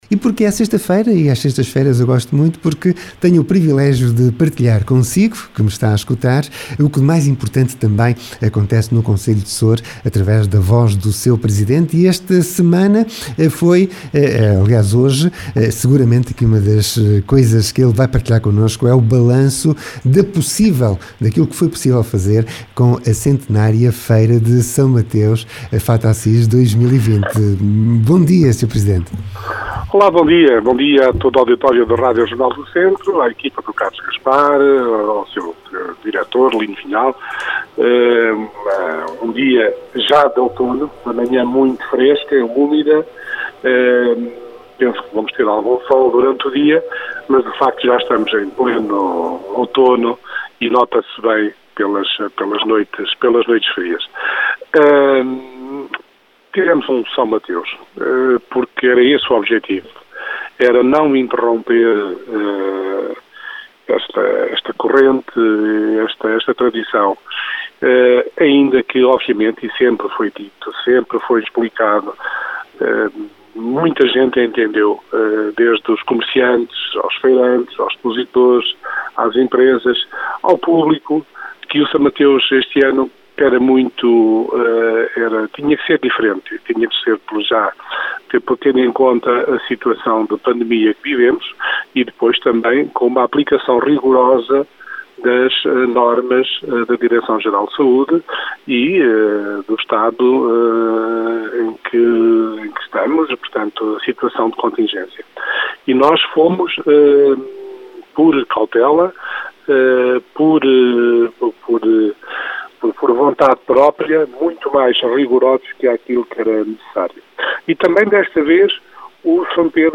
O Balanço (possível) feito por Mário Jorge Nunes, Presidente da Câmara Municipal de Soure, da Feira de S Mateus.